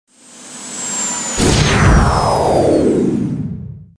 jump_out.wav